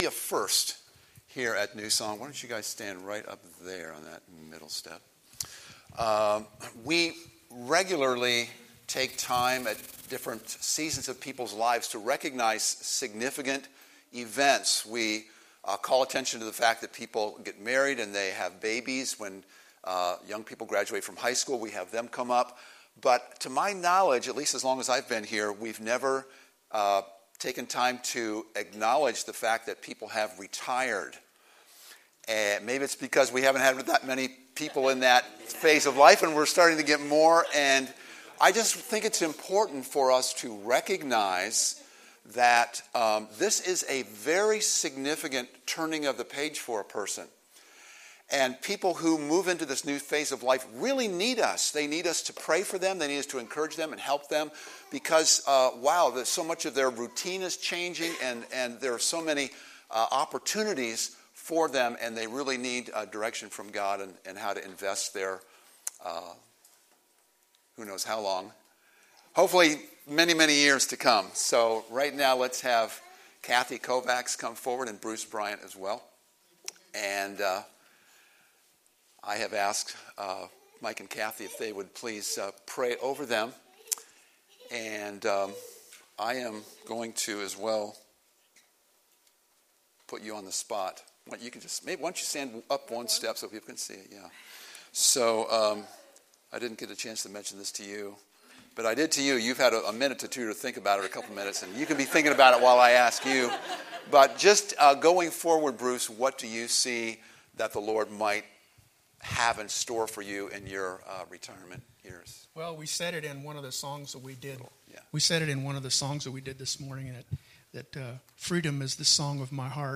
Sermons | New Song Community Church